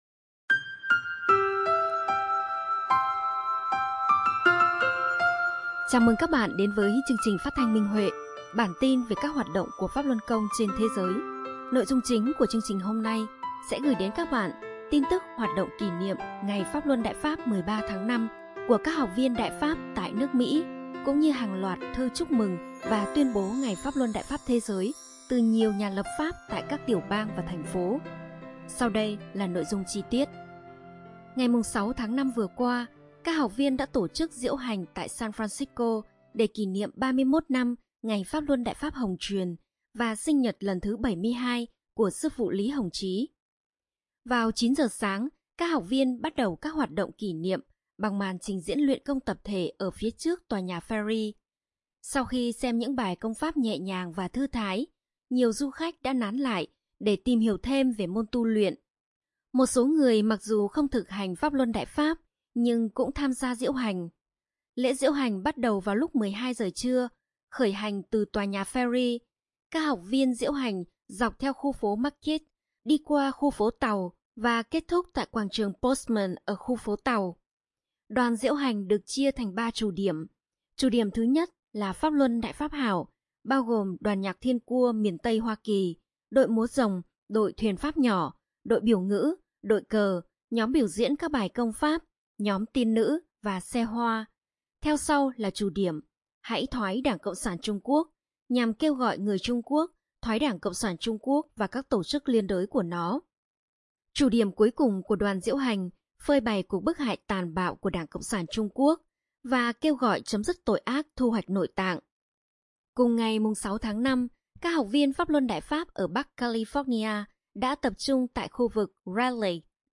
Chương trình phát thanh số 28: Tin tức Pháp Luân Đại Pháp trên thế giới – Ngày 15/5/2023